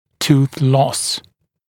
[tuːθ lɔs][ту:с лос]потеря зуба, потеря зубов